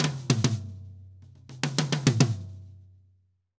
Drum_Break 100_2.wav